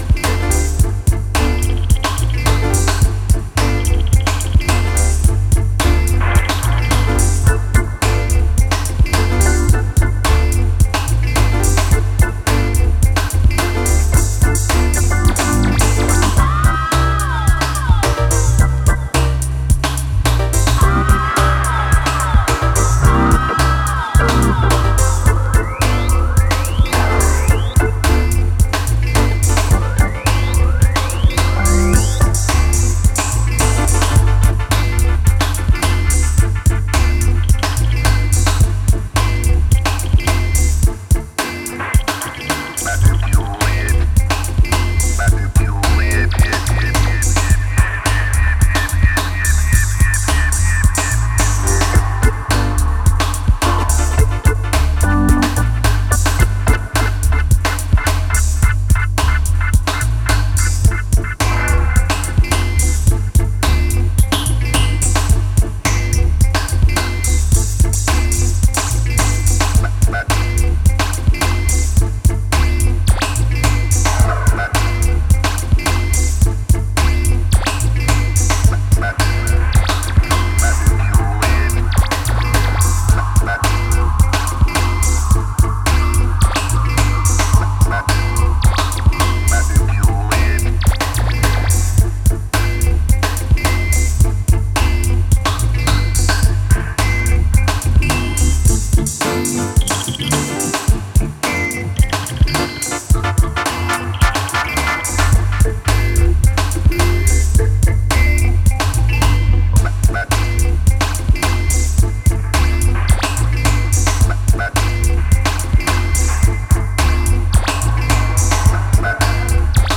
ソリッドでダビーなブレイクビーツ〜ダウンテンポ路線に仕上がっていて全曲それぞれナイス。